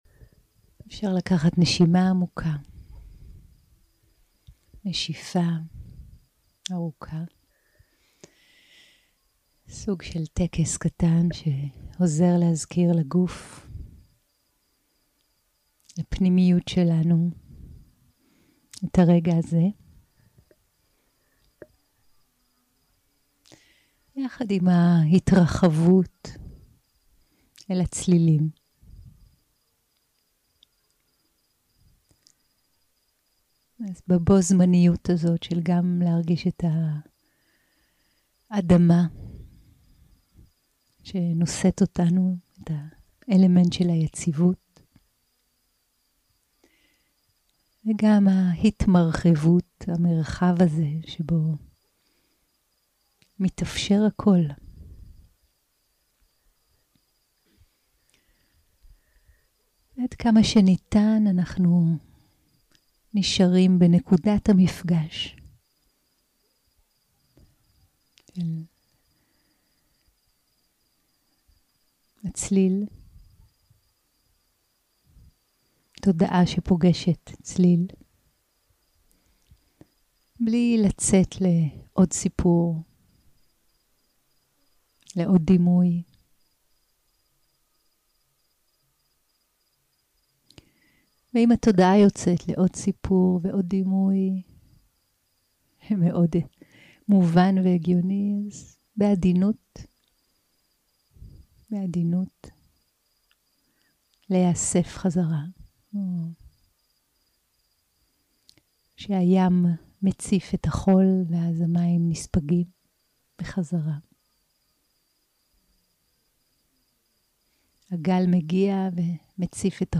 יום 1 - הקלטה 1 - ערב - מדיטציה מונחית - התחלה, קרקוע ופתיחות 0:00 0:00 סוג ההקלטה: Dharma type: Guided meditation שפת ההקלטה: Dharma talk language: Hebrew